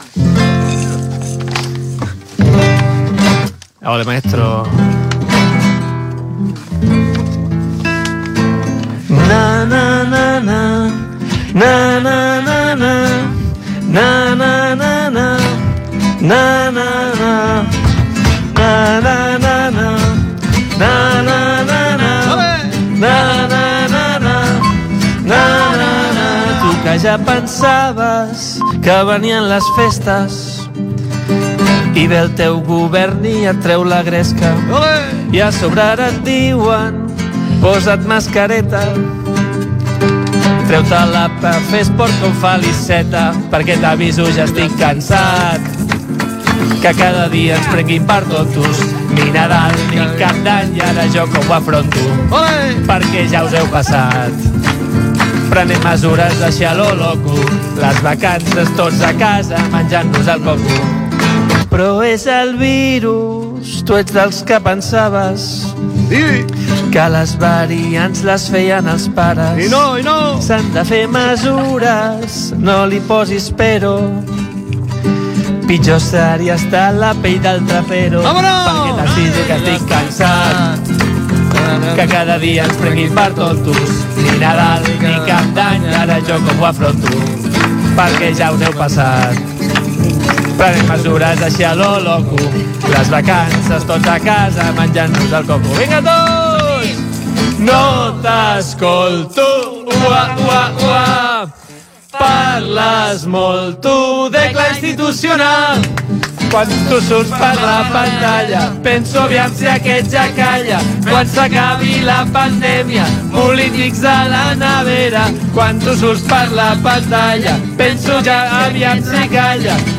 Especial nadalenc. Cançó satírica de la situació del moment
Programa presentat per Agnès Marquès.